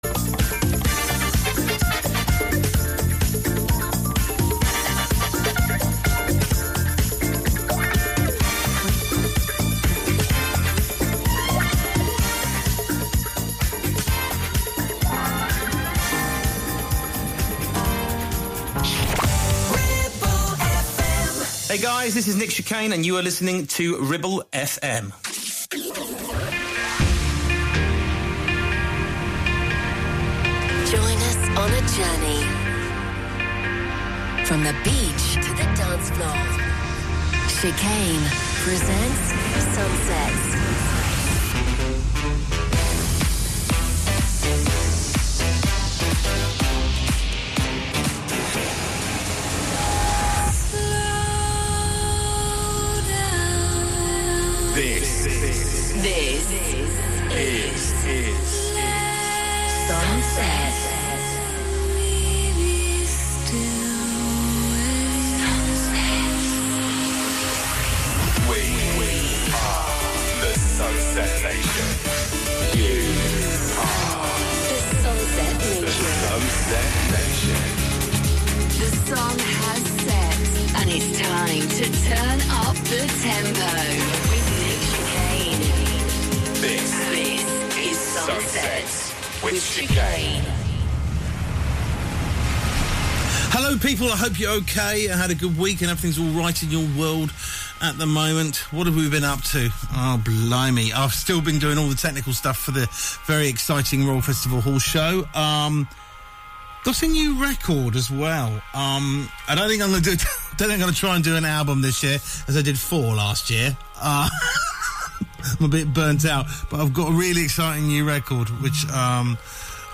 Ribble FM Sun Sets A wonderful way to start your weekend, chilled start and raising the tempo.